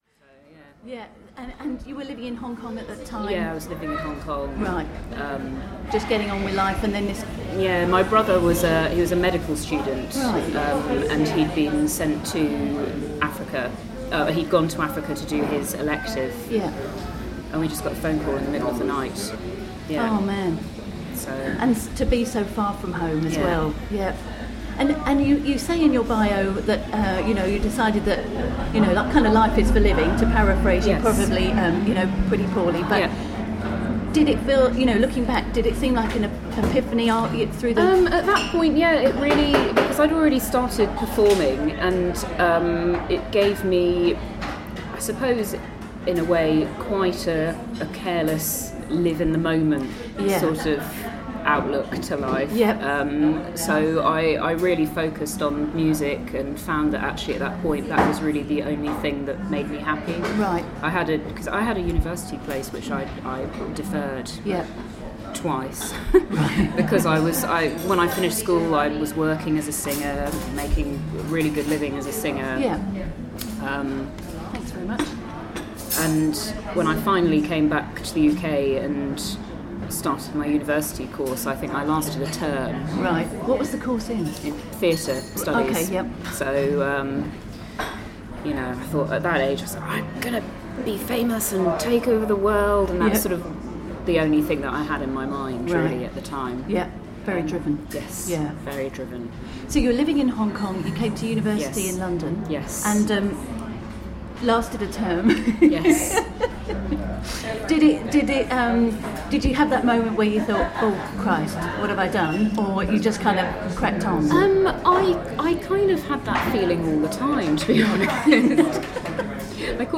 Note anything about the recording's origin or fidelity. You’ll find below the ‘no music’ version of our chat.